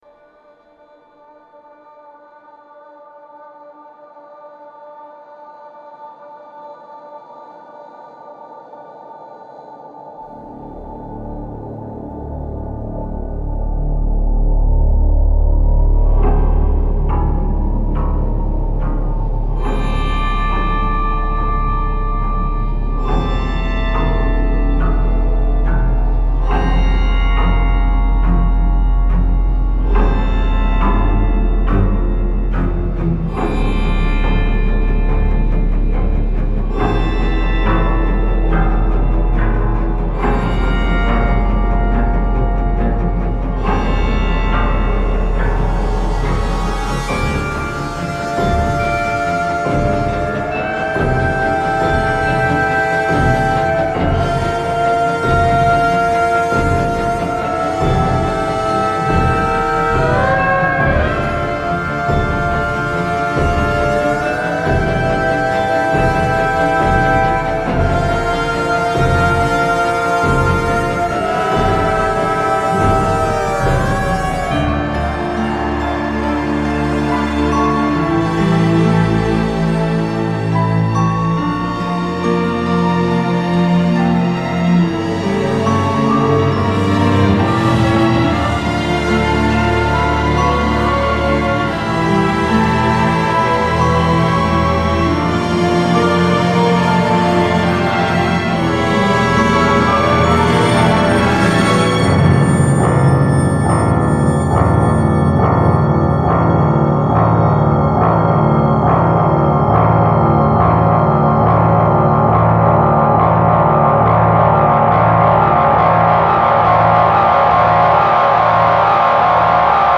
Banda sonora completa